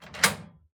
doorunlock.ogg